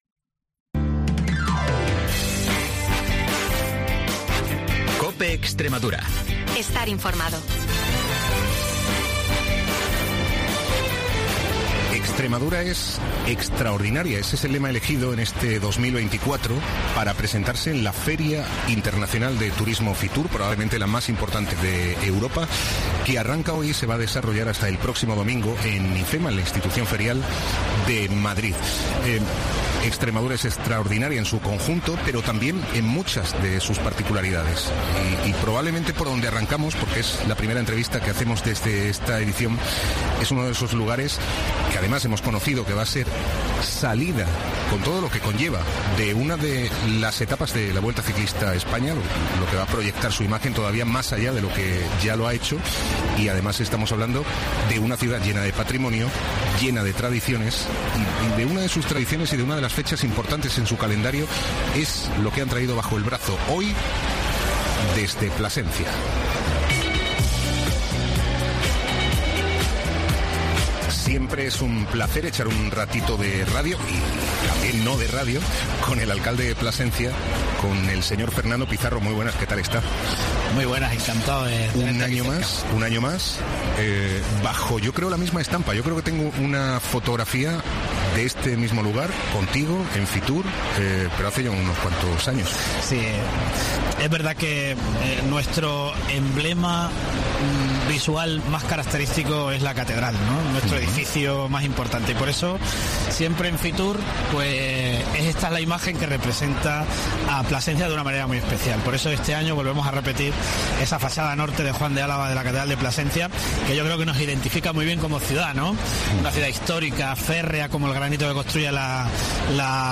Fernando Pizarro, alcalde de Plasencia en Herrera en Cope Extremadura desde FITUR 2024